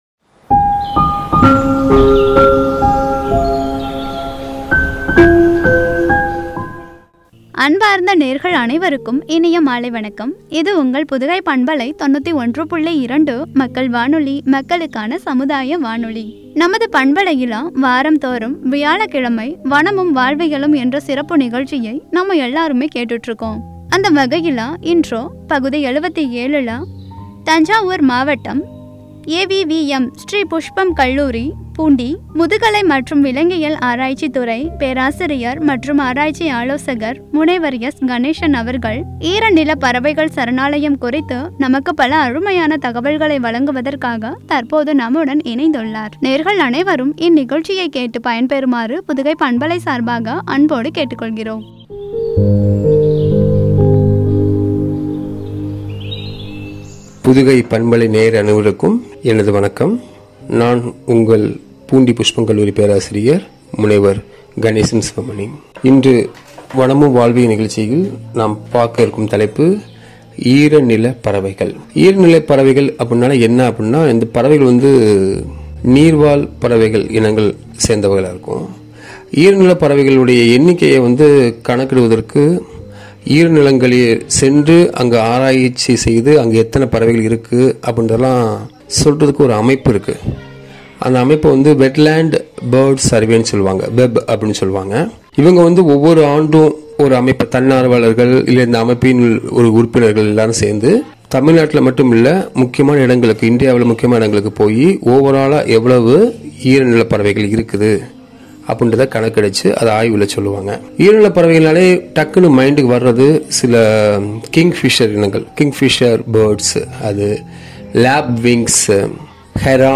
“ஈரநில பறவைகள் சரணாலயம்”என்ற தலைப்பில் வழங்கிய உரை.